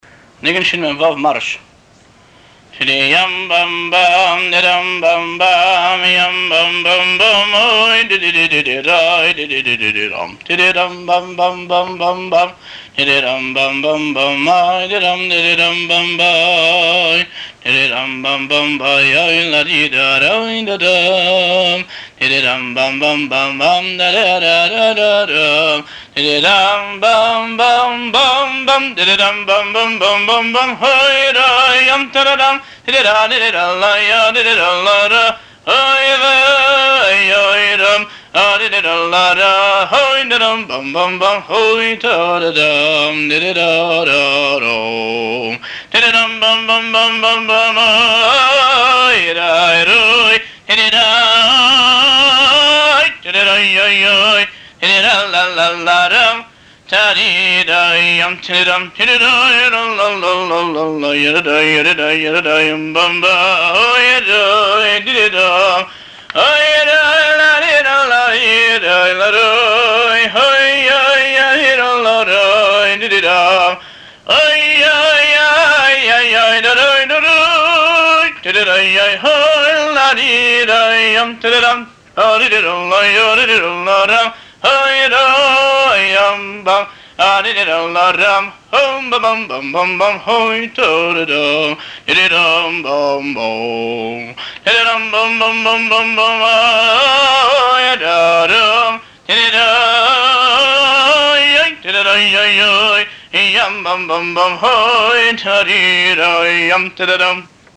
ניגון נצחון או מארש הינו ניגון מסוג הניגונים אותם שרים החיילים בהלכם ובשובם מהמלחמה.